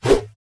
swing2.wav